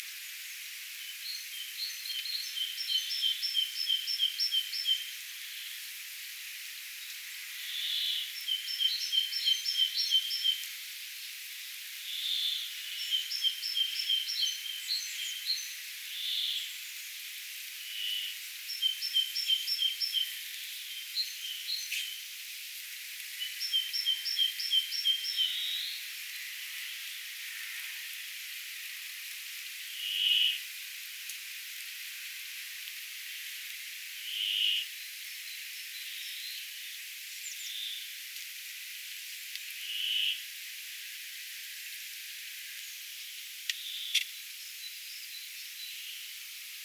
erilaisia järripeippojen laulusäkeitä
Jokaisella järripeippokoiraalla on omanlaisensa säe?
säe on lyhyt ja ikään kuin yksinkertainen
ja sitä toistetaan usein.
erilaisia_jarripeipon_laulusakeita.mp3